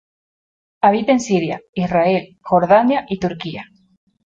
Si‧ria
/ˈsiɾja/